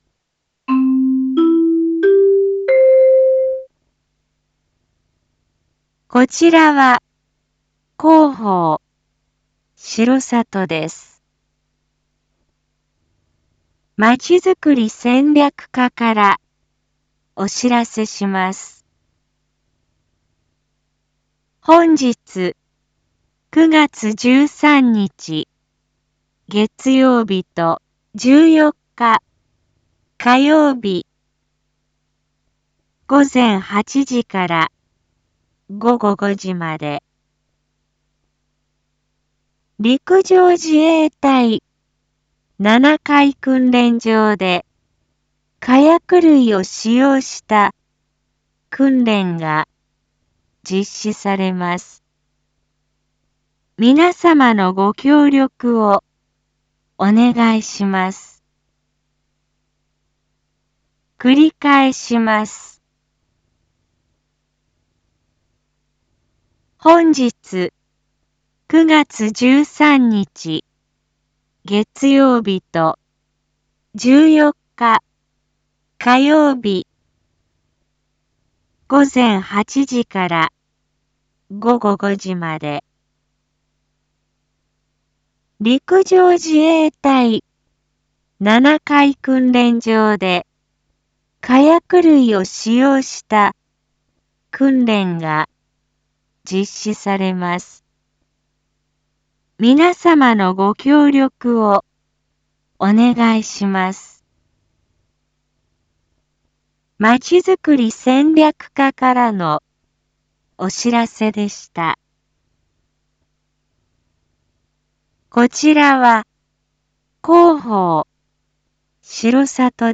一般放送情報
Back Home 一般放送情報 音声放送 再生 一般放送情報 登録日時：2021-09-13 07:02:09 タイトル：R3.9.13 7時放送 (七会地区のみ放送) インフォメーション：こちらは広報しろさとです。